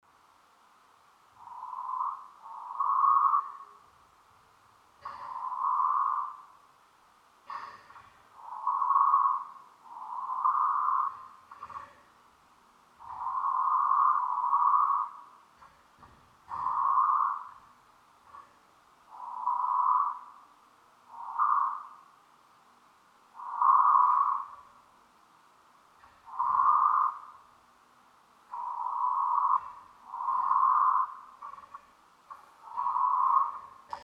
King Quail Sound Effect Download: Instant Soundboard Button
Bird Sounds558 views